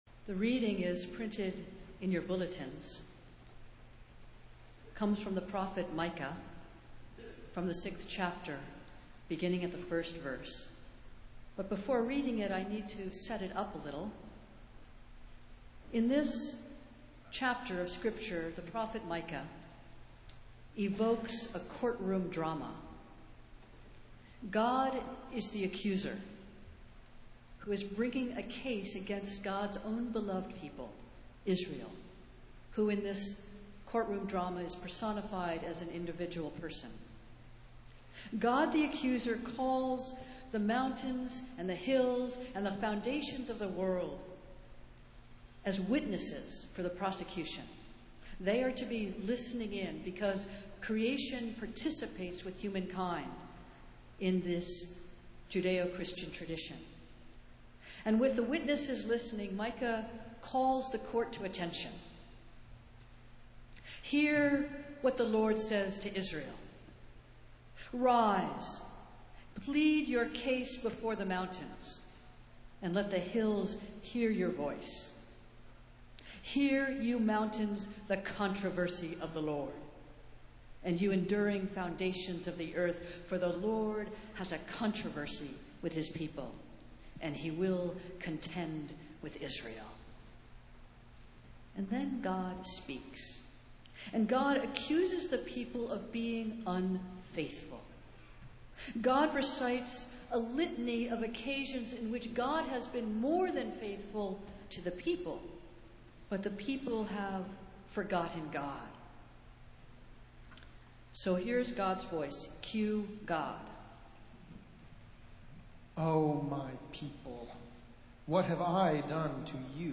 Festival Worship - Welcome Back Sunday